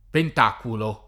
pentaculo [ pent # kulo ]